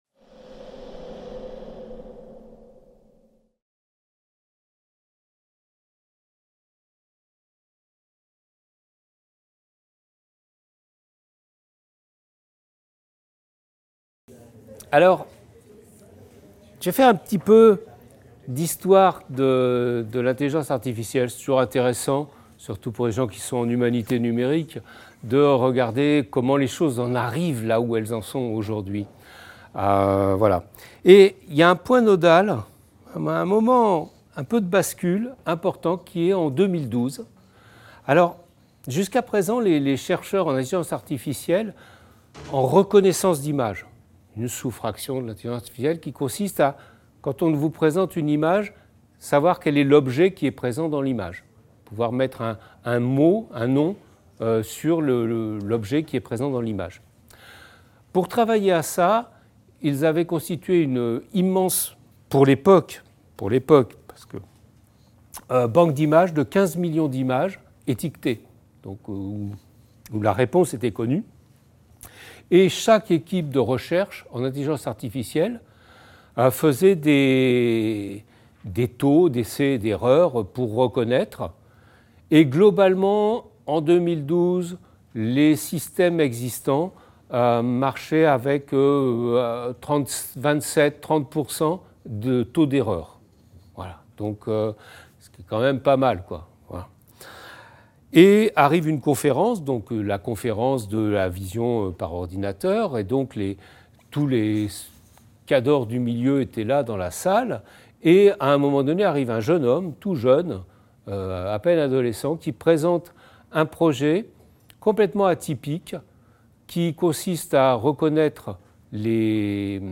Cours de Culture numérique 2023-2024